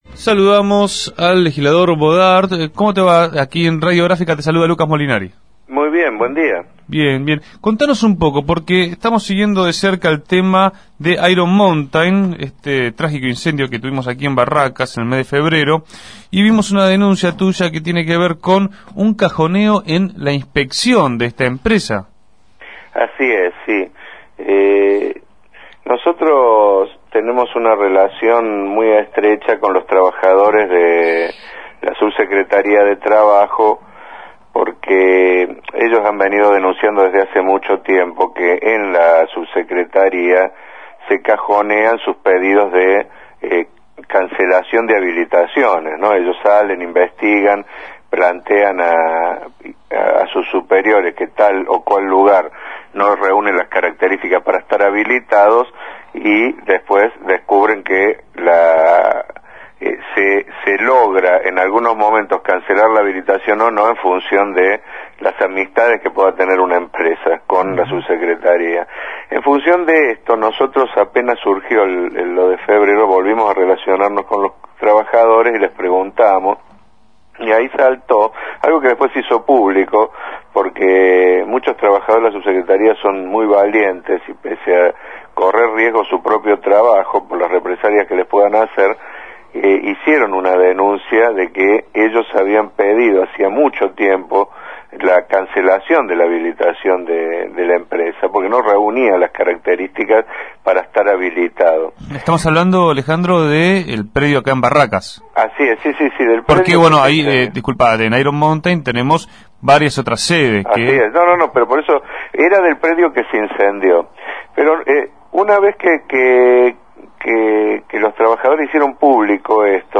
Entrevistado en Punto de Partida, señaló que tiene contacto con los trabajadores estatales de la cartera y ellos ratificaron estas acusaciones.